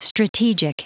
Prononciation du mot strategic en anglais (fichier audio)
Prononciation du mot : strategic